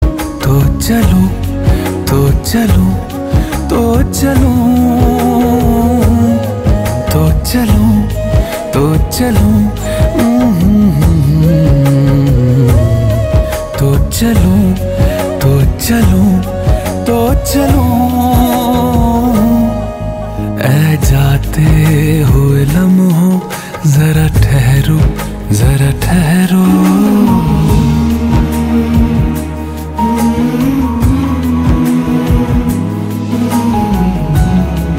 Emotional patriotic Bollywood ringtone